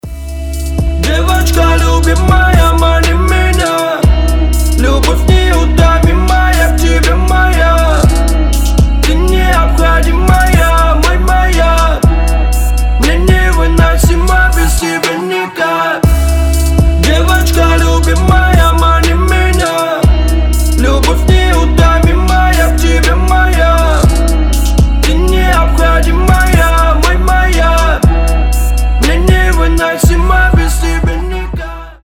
• Качество: 320, Stereo
мужской вокал
лирика
русский рэп